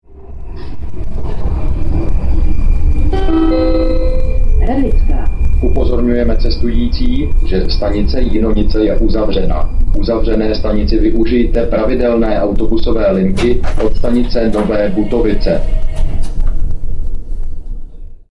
Informovanost cestujících je zajištěna formou hlášení přímo v soupravách metra.
- Hlášení po příjezdu do stanice Radlická (směr Zličín) si